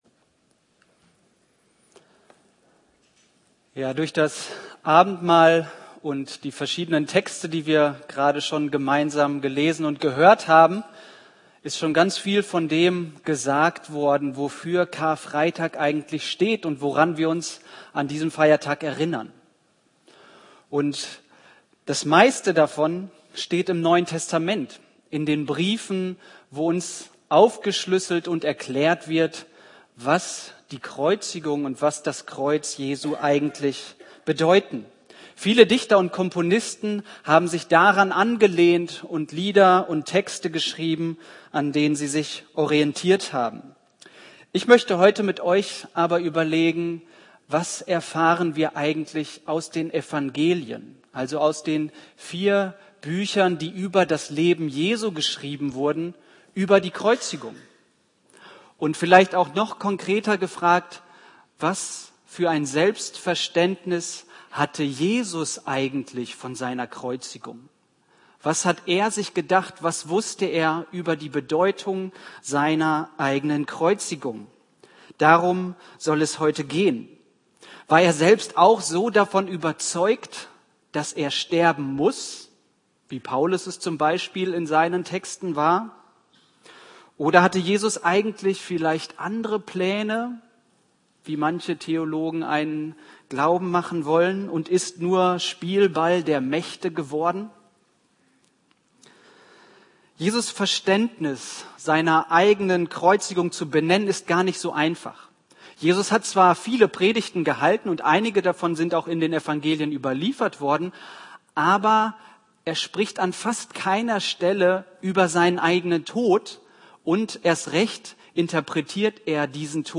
Karfreitag-Gottesdienst vom 29.03.24.
Predigt